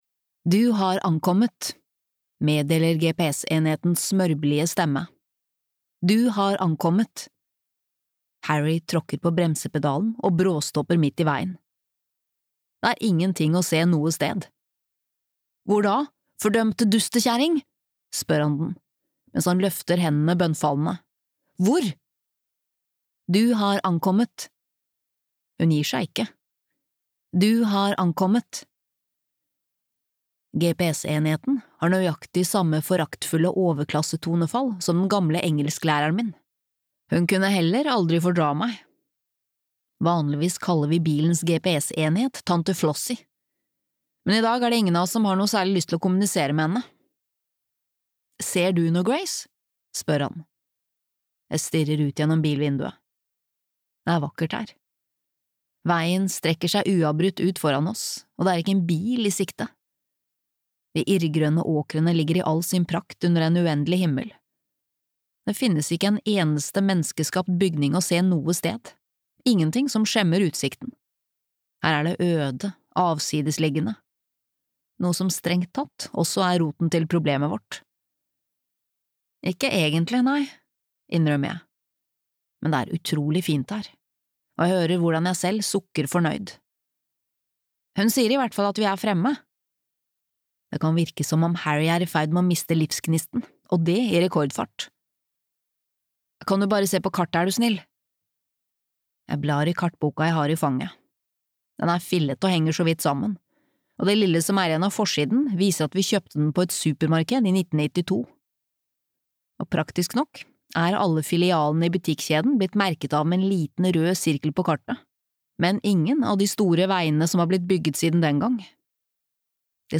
En hytte ved havet (lydbok) av Carole Matthews